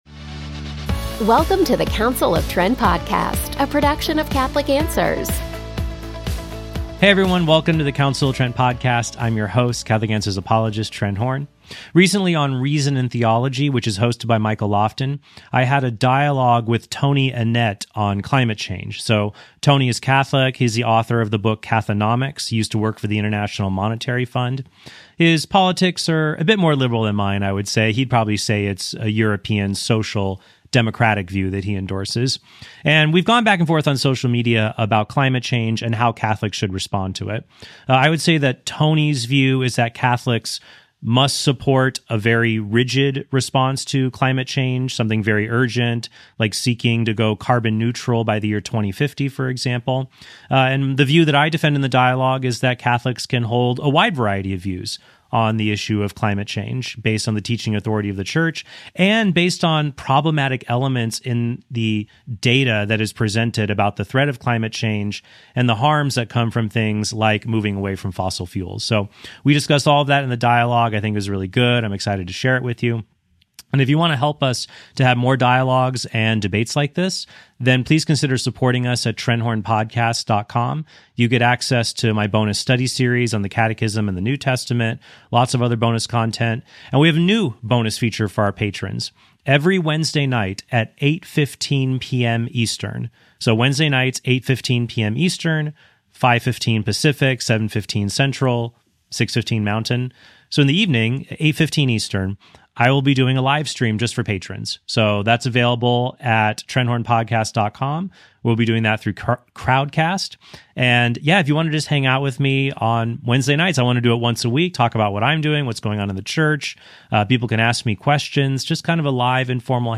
DIALOGUE: What Should Catholics do About Climate Change?